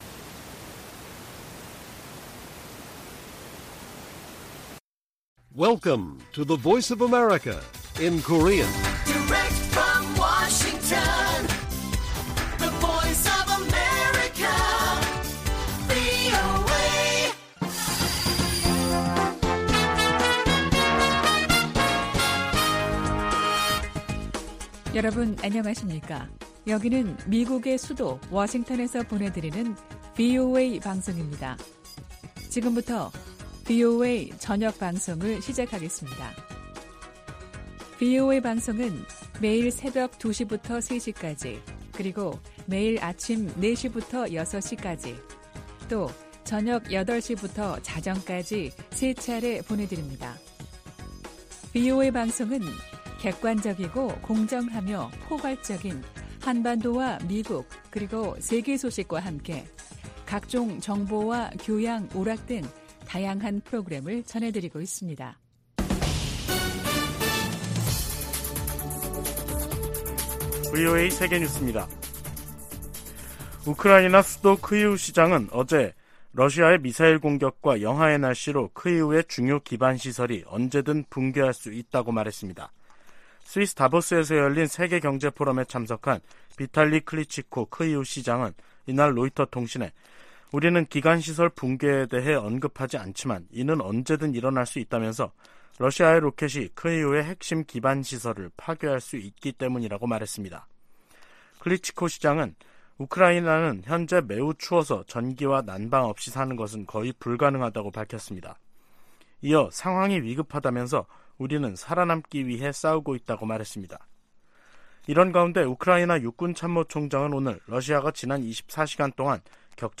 VOA 한국어 간판 뉴스 프로그램 '뉴스 투데이', 2023년 1월 17일 1부 방송입니다. 미국 해군참모총장은 한국의 ‘자체 핵무장’ 안과 관련해 미국의 확장억제 강화를 현실적 대안으로 제시했습니다. 유엔은 핵보유국 의지를 재확인한 북한에 긴장 완화를 촉구하고 유엔 결의 이행과 외교를 북핵 문제의 해법으로 거듭 제시했습니다.